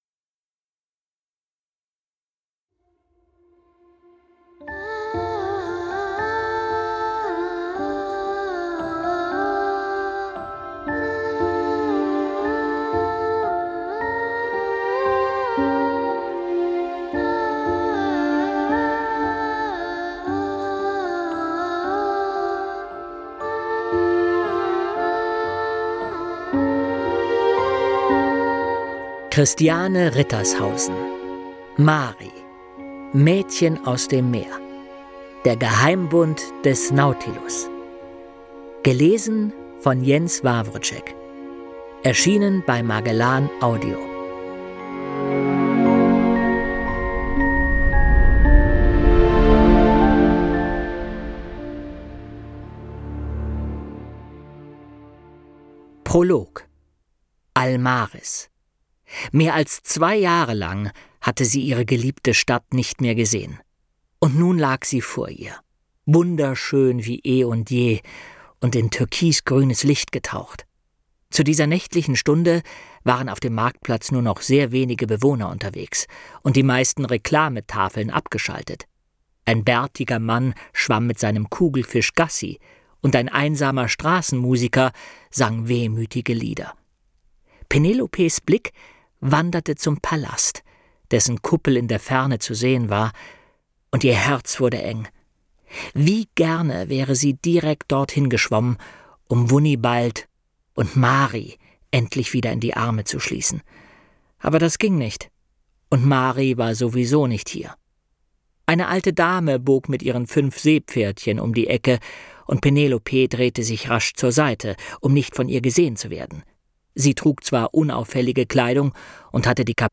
Jens Wawrczeck (Sprecher)